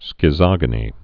(skĭ-zŏgə-nē, skĭt-sŏg-)